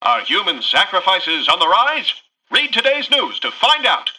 [[Category:Newscaster voicelines]]
Newscaster_headline_11.mp3